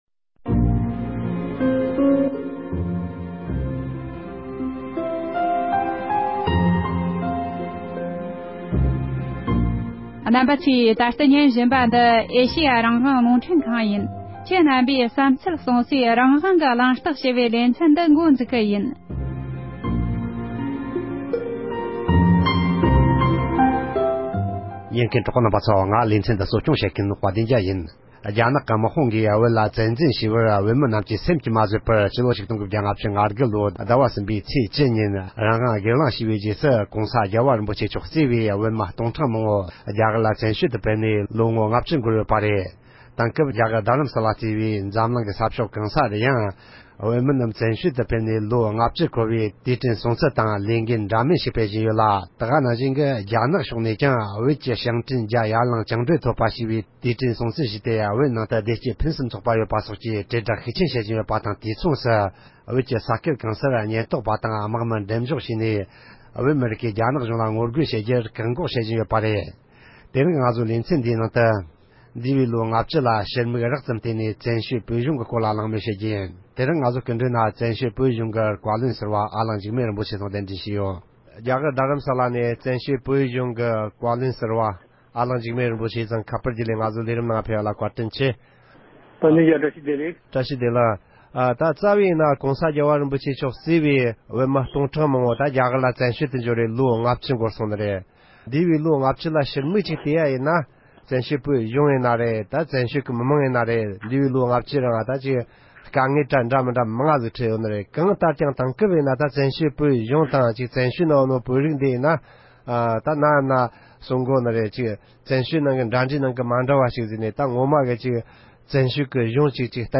གླེང་མོལ་ཞུས་པ་ཞིག